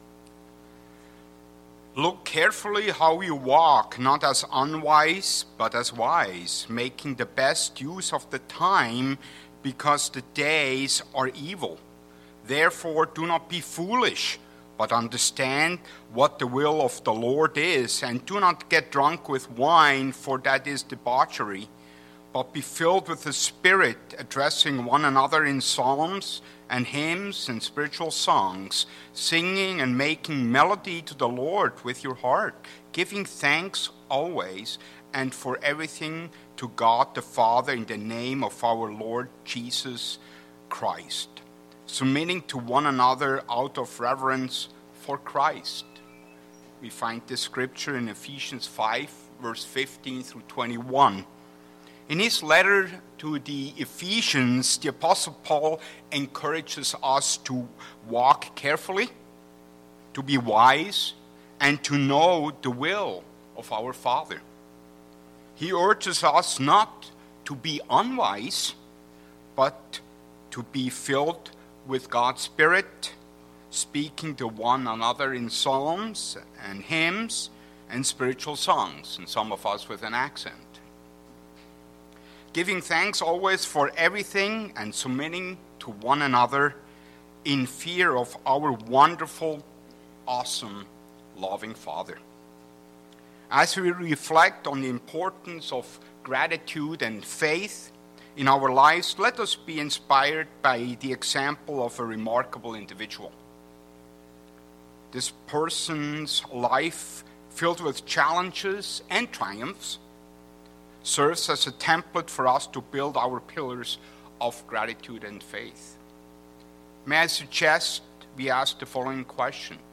The sermon explores seven pillars of gratitude and faith inspired by the life of William Bradford, a key figure among the Pilgrims. These pillars include drawing strength from biblical characters, acting on faith, stepping out in faith, humbly relying on God, living as a living sacrifice for others, fulfilling people's trust, and always giving thanks.
Given in Eau Claire, WI